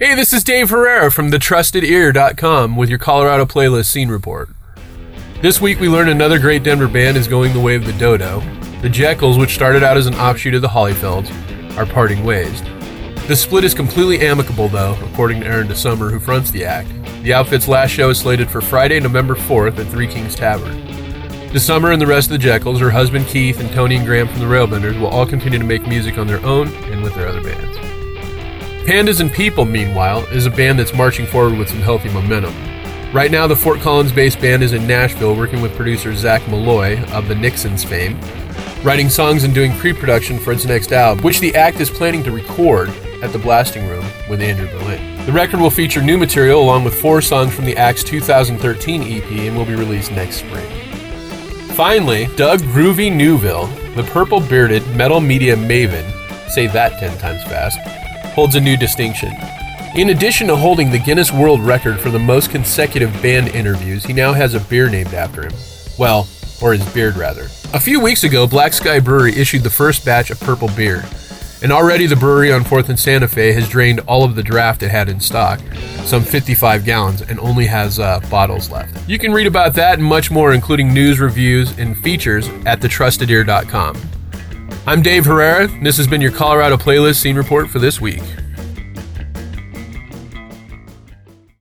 Music bed courtesy of Jux County from their new album Coral.
TRUSTED EAR SCENE REPORT